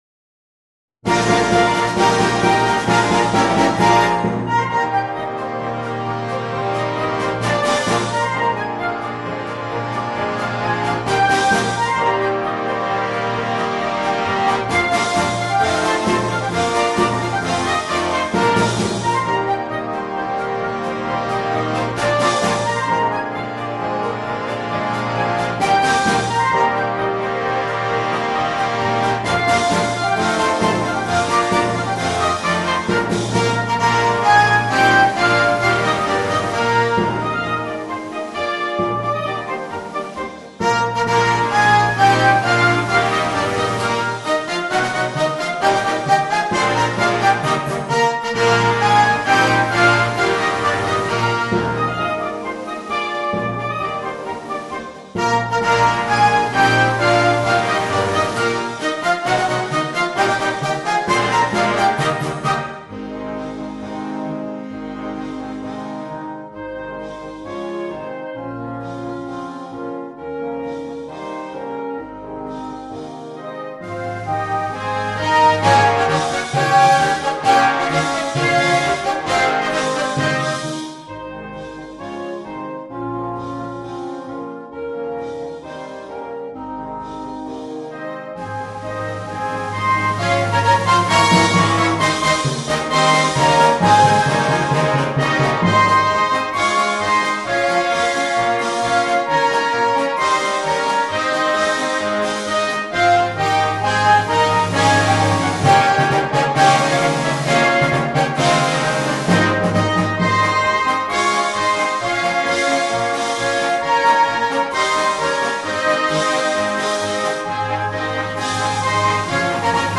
Nello stile di Strauss ecco un galop brillante e piacevole.